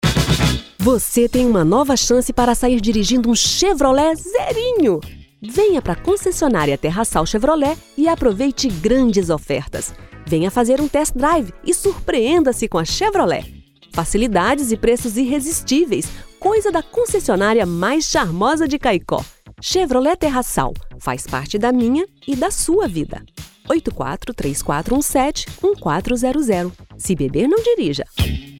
Feminino